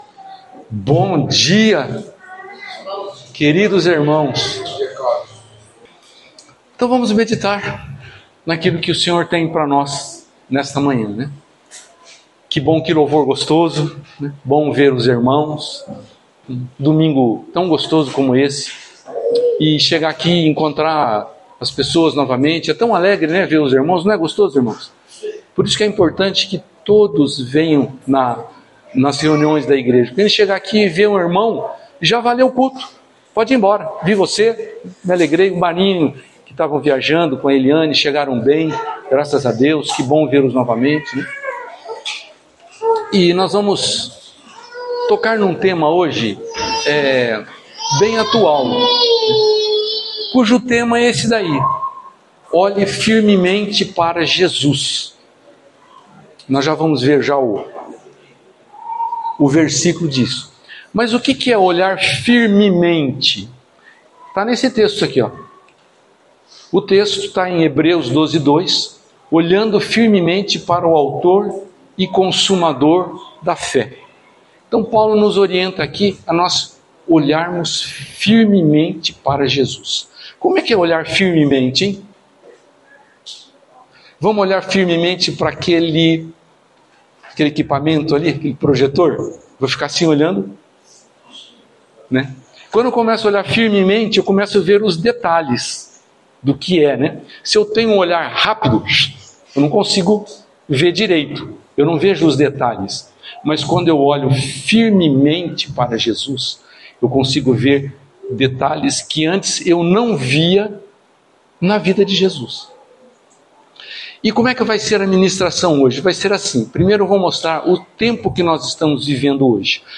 Palavra ministrada
no culto do dia 27/04/2025